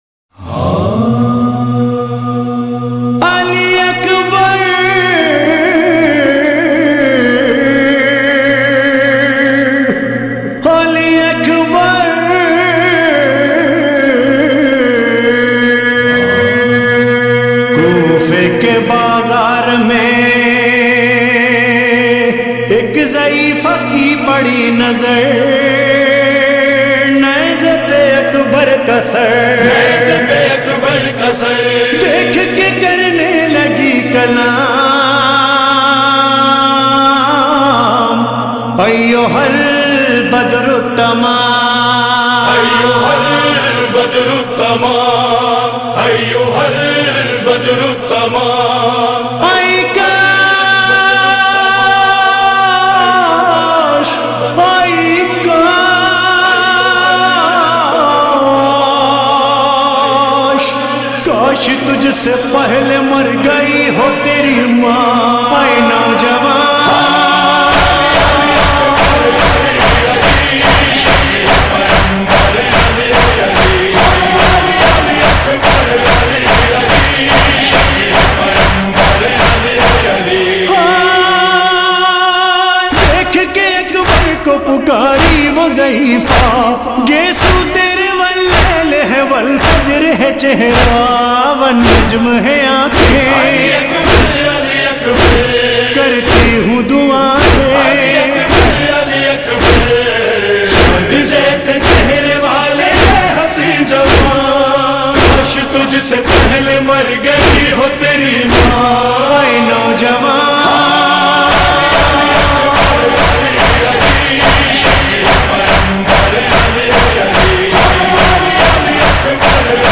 Noha Khawan
he have a most heart touching voice